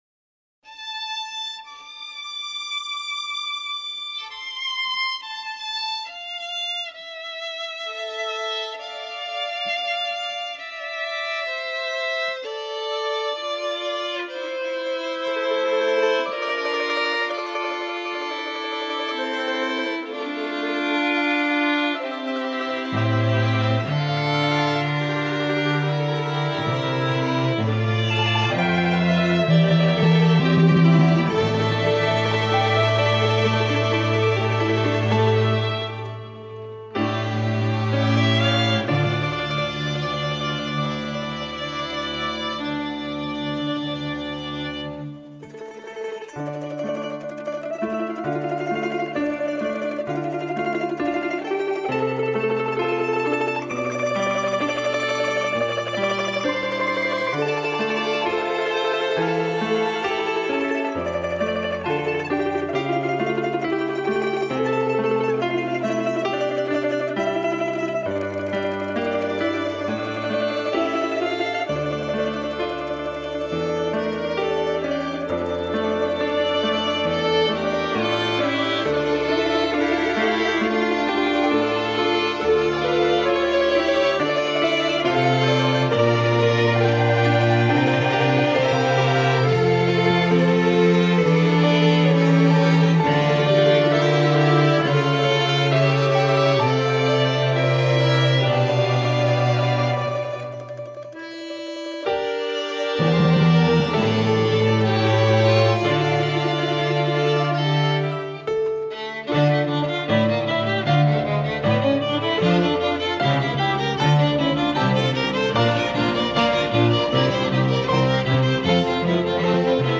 Оцените, плиз, сведение оркестра...
Прежде всего слышно что многие инструменты примерно на одном уровне по глубине, а должно быть грамотно расставлено.
Не многое удается сделать с тюнингом строя. И с ритмикой также.